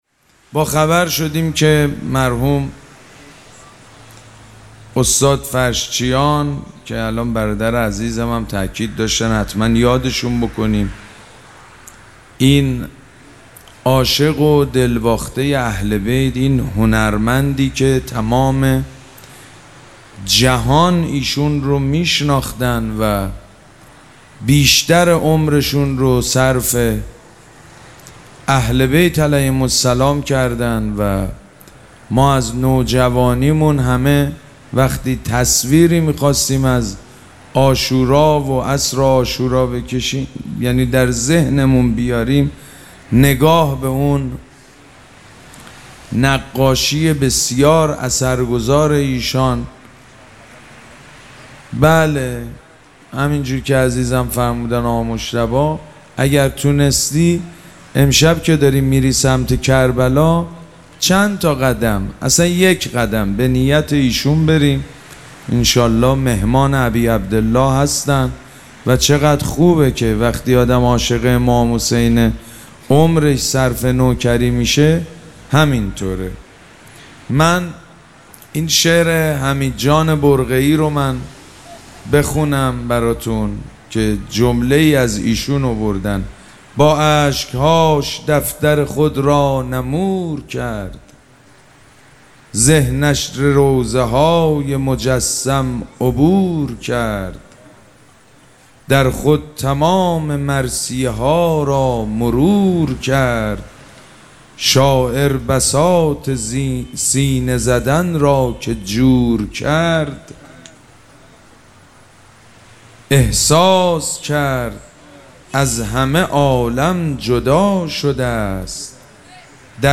شب دوم مراسم عزاداری اربعین حسینی ۱۴۴۷